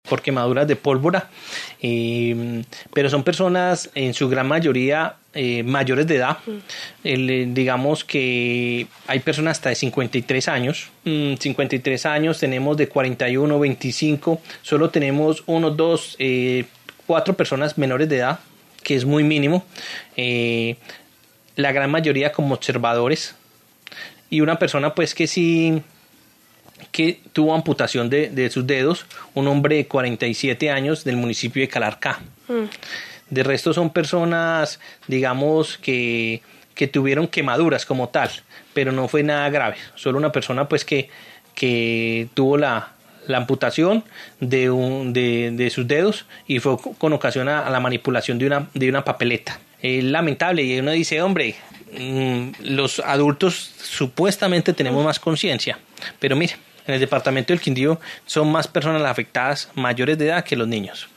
Secretario del Interior del Quindío